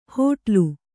♪ hōṭlu